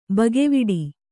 ♪ bageviḍi